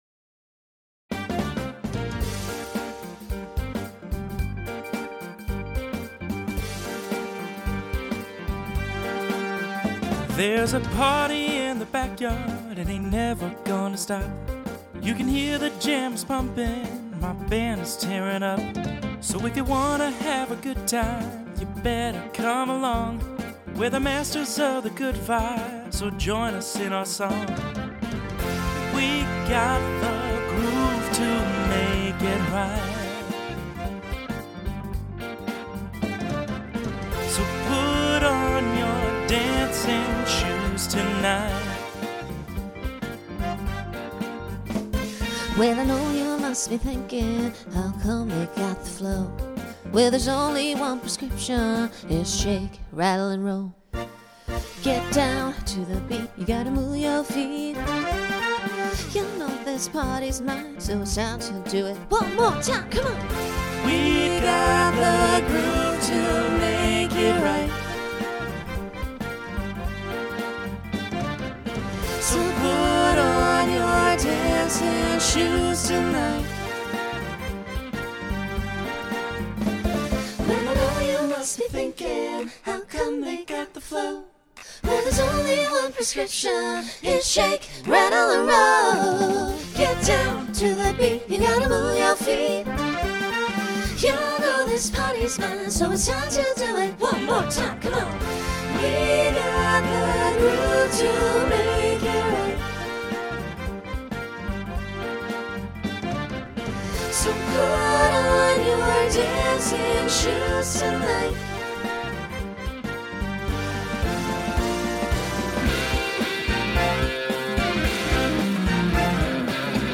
Begins with a small group to facilitate costume change.
Genre Rock Instrumental combo
Transition Voicing SATB